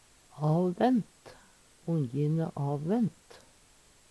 avvent - Numedalsmål (en-US)